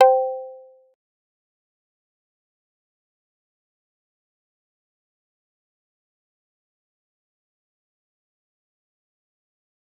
G_Kalimba-C5-pp.wav